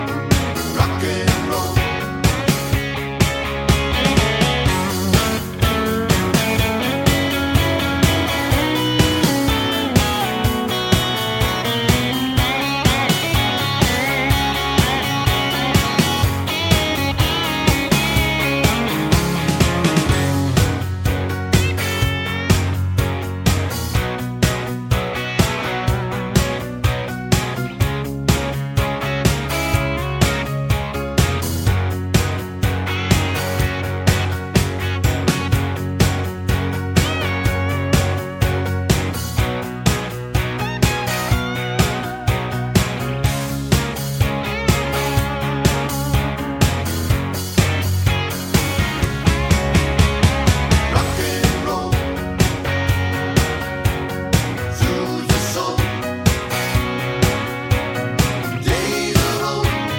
no sax solo Rock 'n' Roll 3:19 Buy £1.50